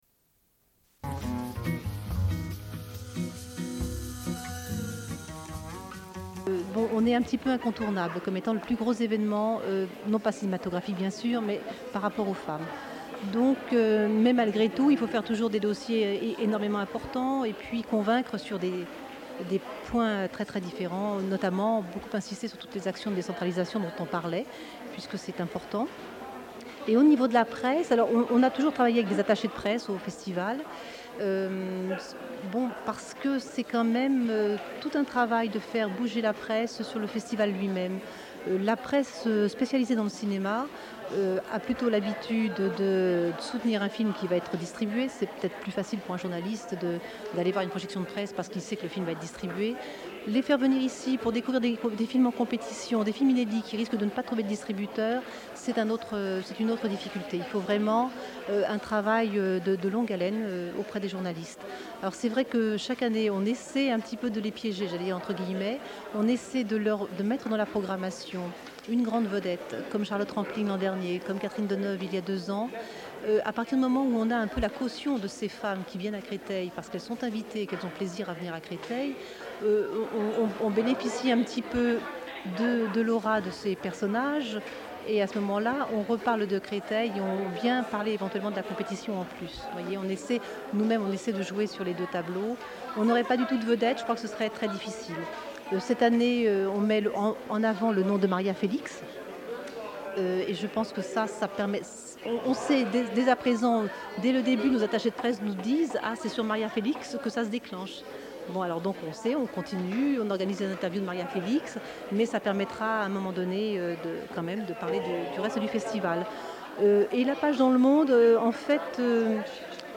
Une cassette audio, face A
Radio Enregistrement sonore